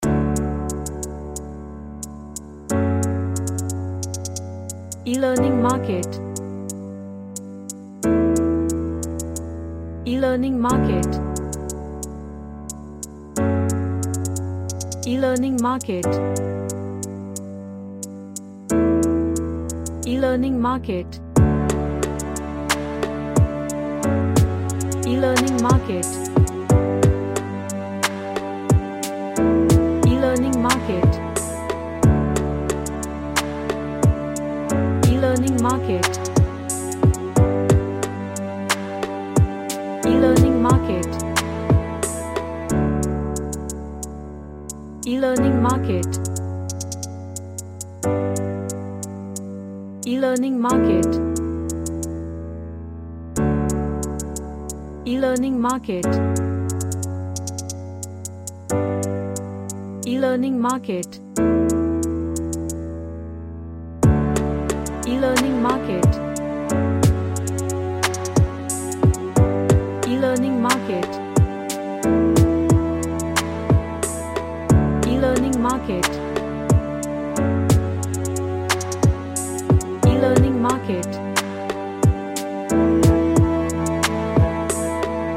A Orchestral emotional track featuring ensemble.
Emotional